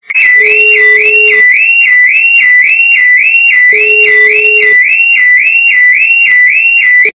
При прослушивании на СМС - Автосигнализация без горном качество понижено и присутствуют гудки.
Звук на СМС - Автосигнализация без горном